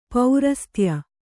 ♪ paurastya